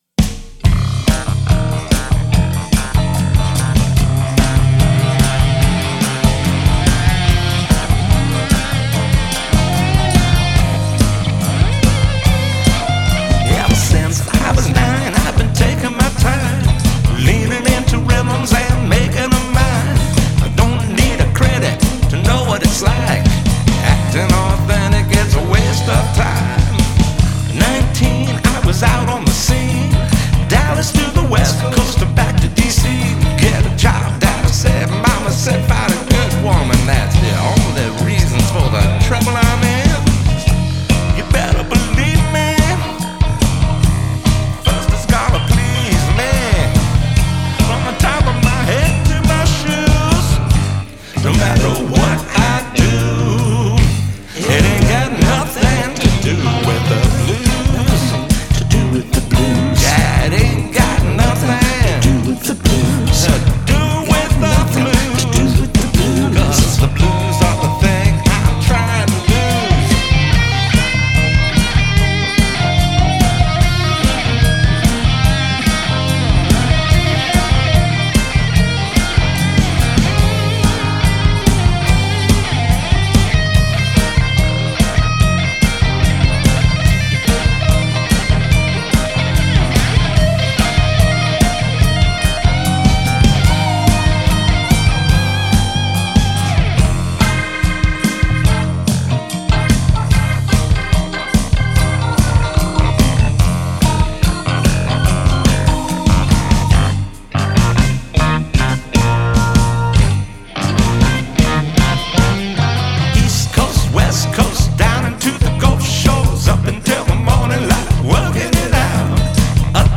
Keyboards / Vocals / Lapsteel / Percussion